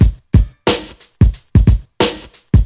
• 119 Bpm 00s Rap Breakbeat G# Key.wav
Free drum loop - kick tuned to the G# note. Loudest frequency: 1012Hz
119-bpm-00s-rap-breakbeat-g-sharp-key-IQy.wav